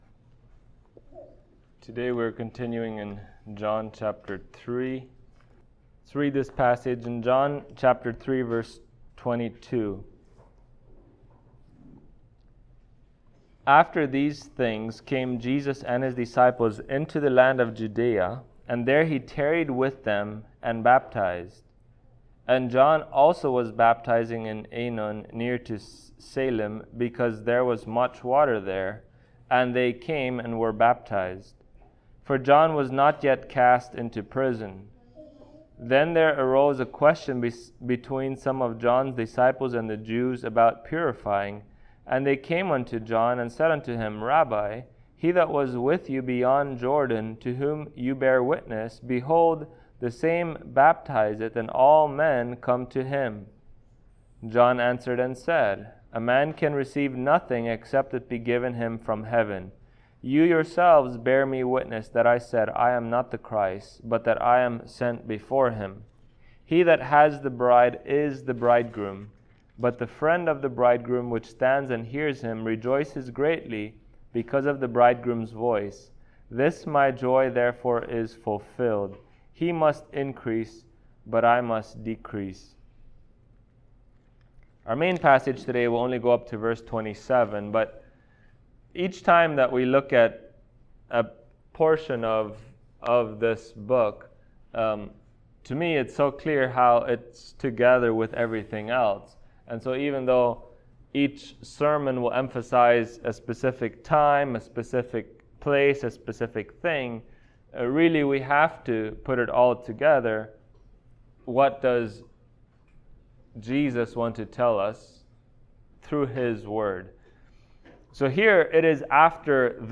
John Passage: John 3:22-27 Service Type: Sunday Morning Topics